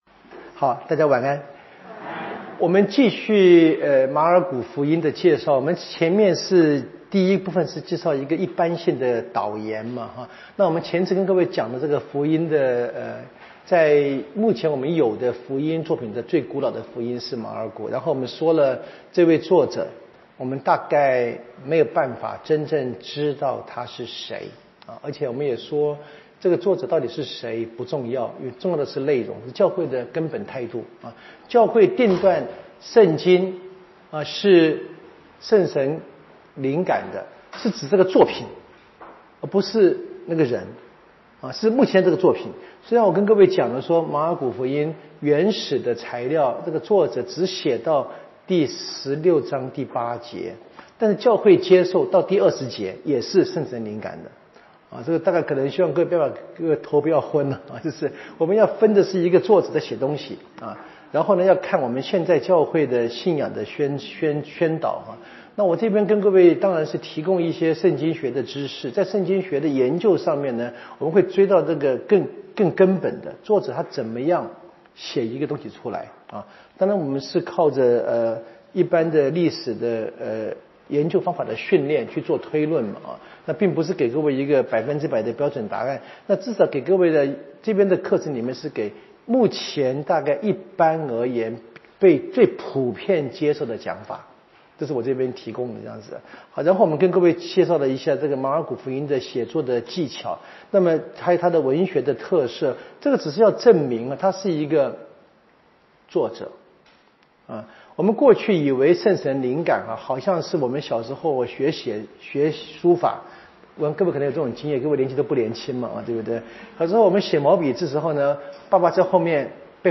【圣经讲座】